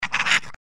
Index of /server/sound/npc/giantrat
giantrat_attack2_01.mp3